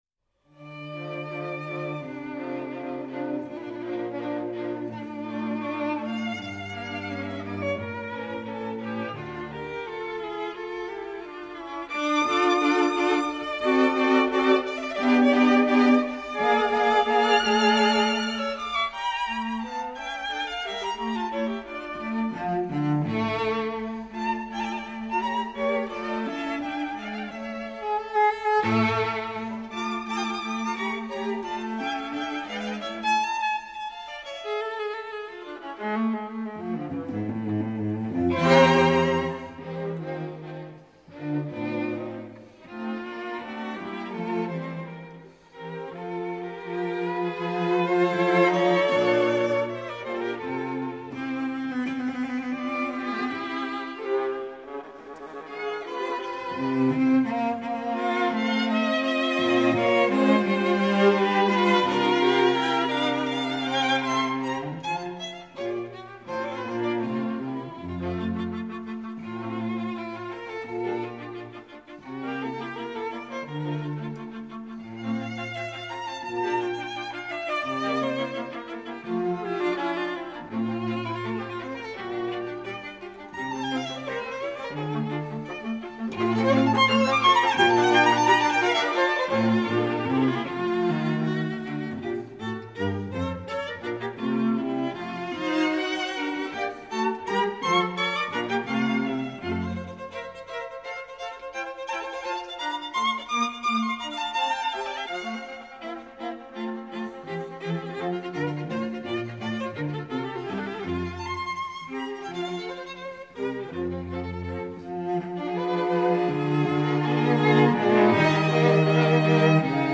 String Quartet in D minor
Allegro